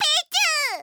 File:Pichu voice sample.oga
Pichu_voice_sample.oga.mp3